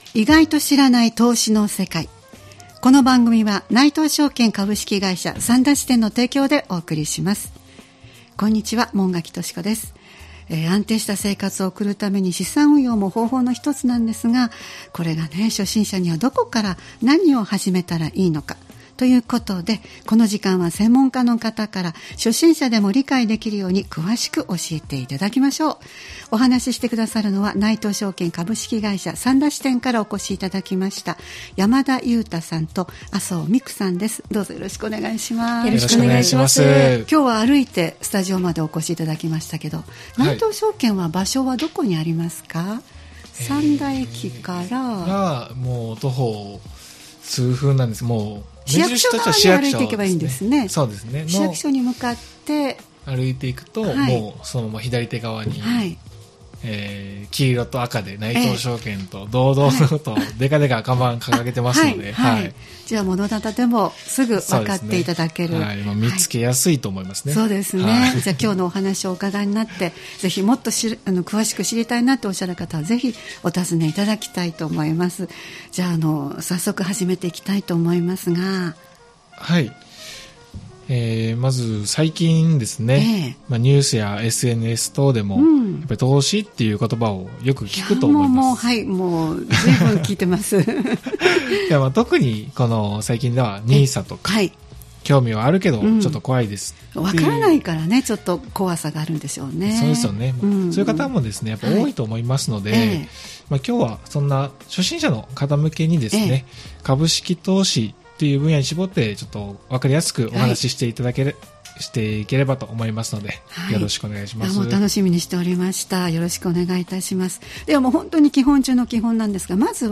内藤証券株式会社 三田支店から専門家の方をお迎えして、初心者の方に向けて、株式投資についてわかりやすく解説していただきました。